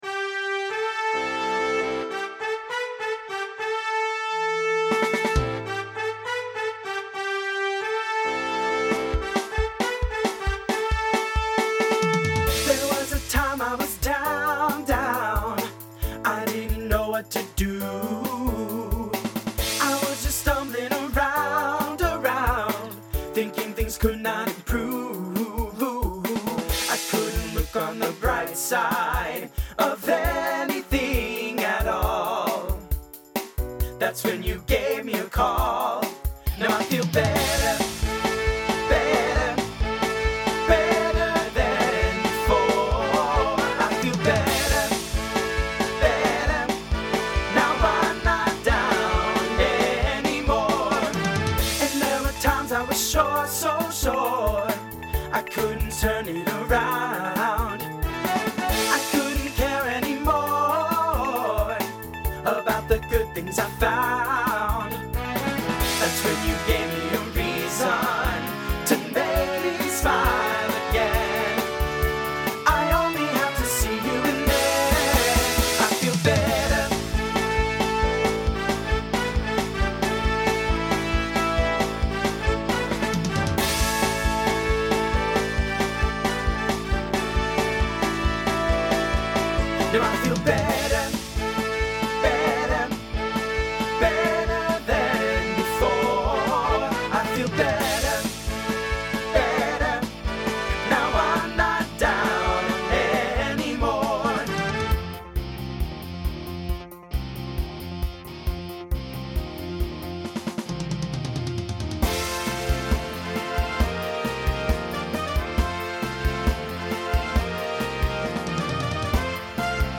TTB/SSA
Voicing Mixed Instrumental combo Genre Pop/Dance